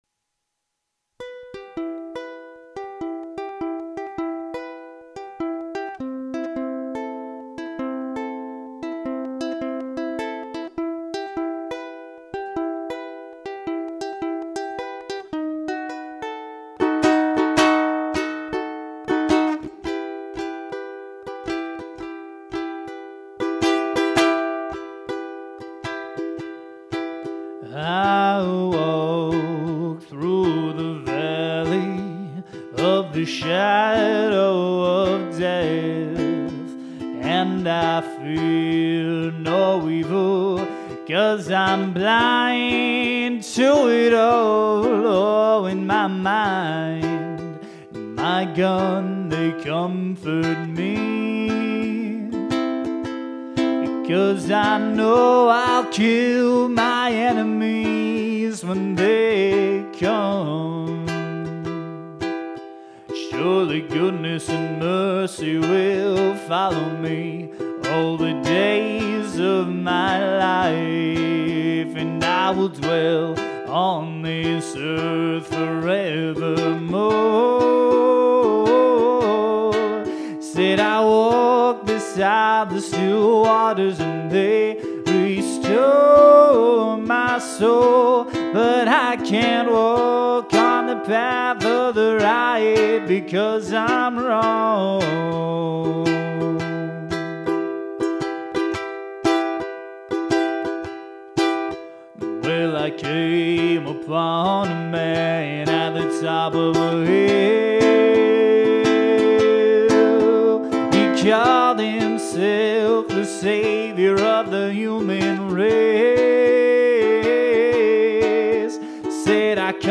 Here's a ukulele cover by yours truly